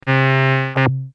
Free MP3 vintage Korg PS3100 loops & sound effects 8